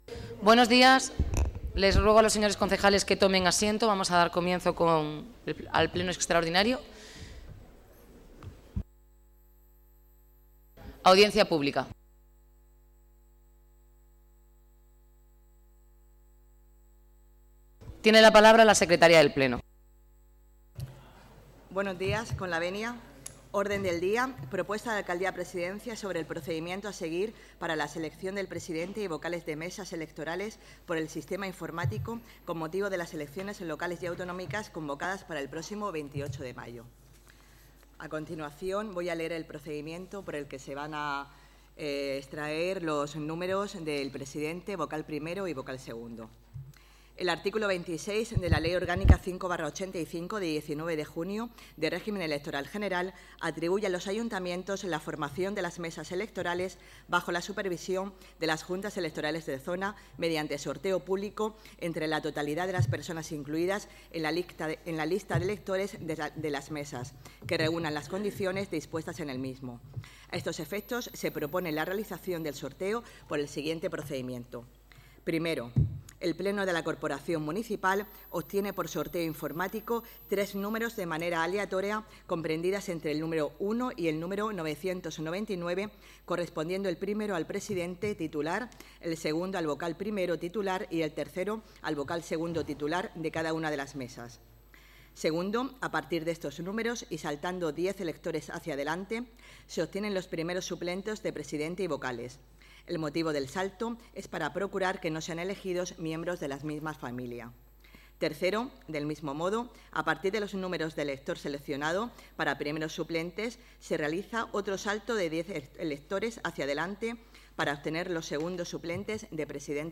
Durante la sesión extraordinaria del pleno, celebrado este viernes, se ha procedido al sorteo p
Enlace a Pleno extraordinario sorteo de mesas electorales